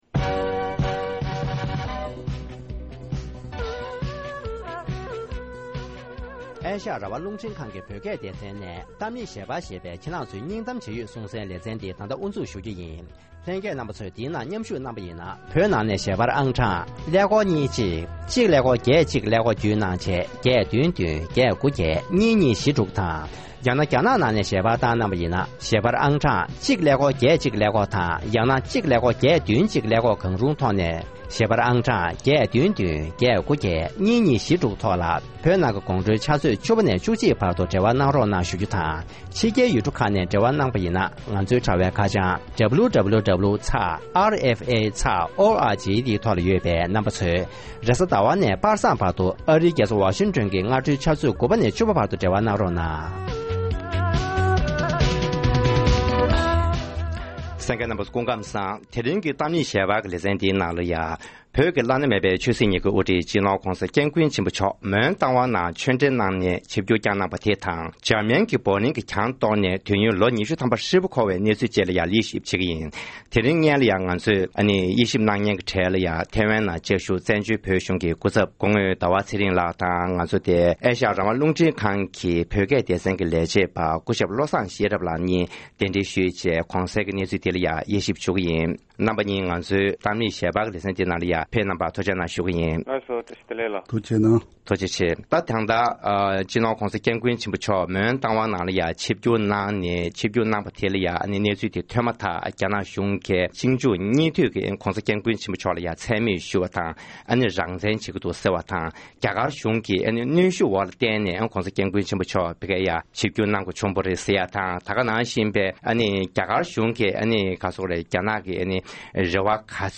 ༄༅༎དེ་རིང་གི་གཏམ་གླེང་ཞལ་པར་གྱི་ལེ་ཚན་ནང་དུ་བོད་མིའི་དབུ་ཁྲིད་སྤྱི་ནོར་༸གོང་ས་༸སྐྱབས་མགོན་ཆེན་པོ་མཆོག་གིས་རྟ་ཝང་ཁུལ་དུ་ཆིབས་བསྒྱུར་བསྐྱངས་པའི་འབྲེལ་ཡོད་གནས་ཚུལ་དང་དེ་བཞིན་རྒྱ་དཀར་ནག་གཉིས་ཀྱི་རྩོད་རྙོག་སོགས་ཀྱི་སྐོར་ལ་བགྲོ་གླེང་ཞུས་པའི་དུམ་མཚམས་གསུམ་པ་དེར་གསན་རོགས༎